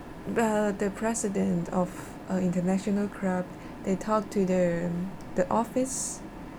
S1 = Brunei female S2 = Chinese female
Intended Word: club Heard as: crowd Discussion: There is [r] rather than [l] in club .